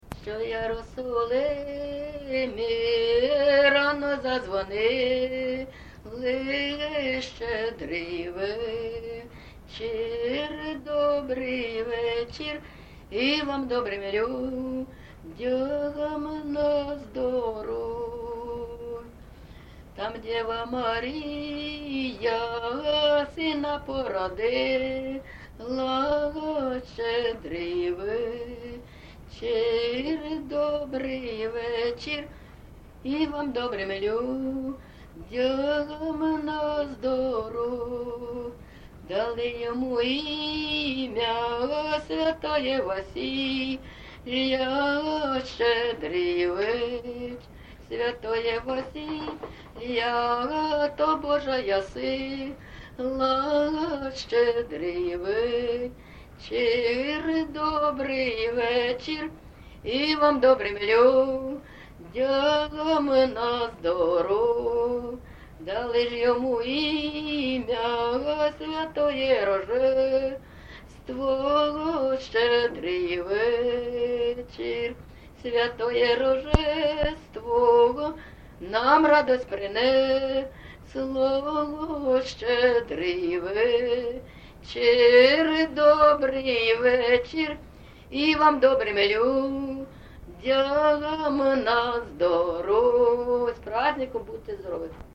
ЖанрЩедрівки
Місце записум. Маріуполь, Донецька обл., Україна, Північне Причорноморʼя